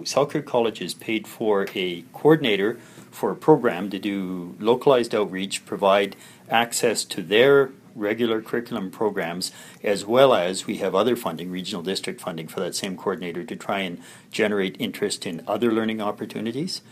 Area Director Garry Jackman says the community learning hub at the Crawford Bay school has opened the doors of study to hundreds of people.